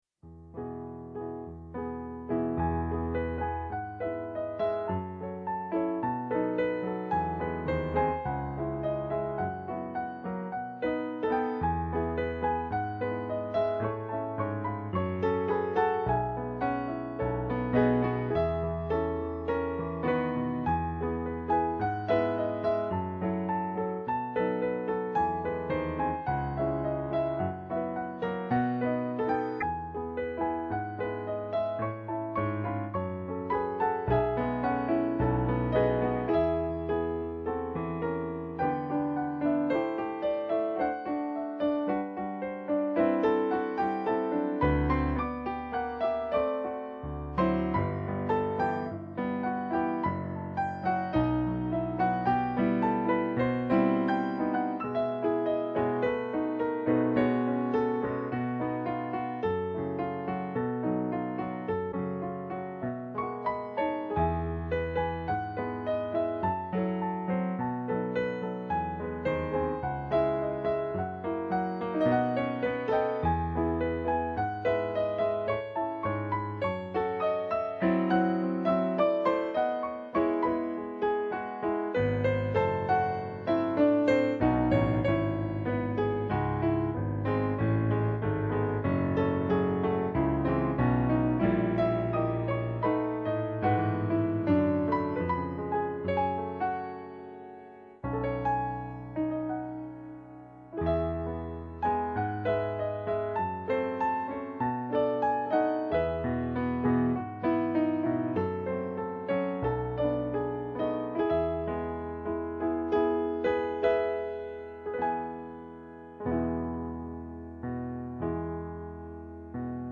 今回の音源は私の練習音源で、まだ発展途上のものだけれども、今後より完成度を上げた形でまた録音したいとは思っている。今回の音源は、まるでコウモリの格好をしたドラキュラがダンスをしているような、ツンツンした演奏だけれど、いつか親密な恋人ができたりすれば、いわゆる「ツンデレ」の「デレ」の面が強く出た、デレデレした演奏になるのかもしれない。